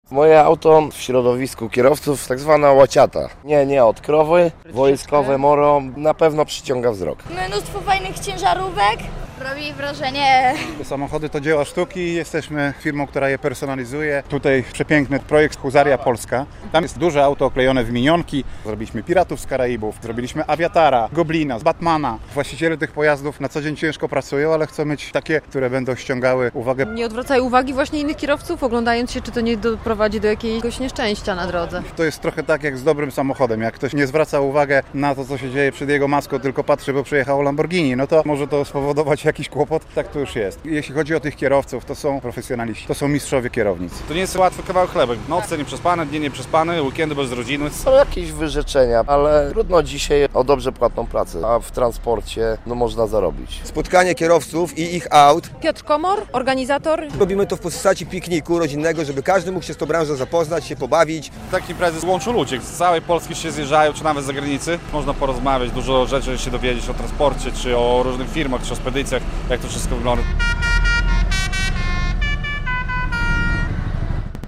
Zlot Pojazdów Ciężarowych w Wasilkowie - relacja